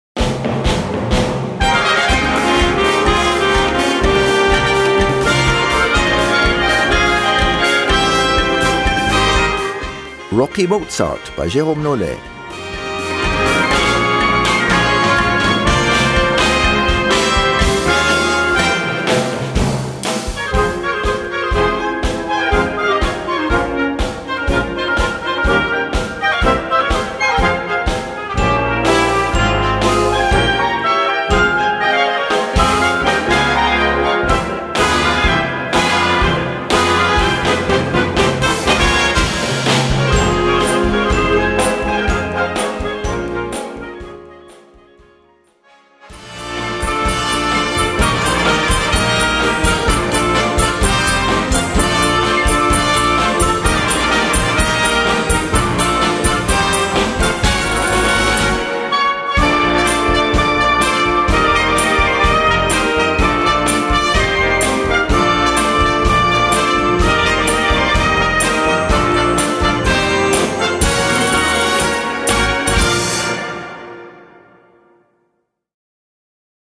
Gattung: Unterhaltungsmusik
6:35 Minuten Besetzung: Blasorchester PDF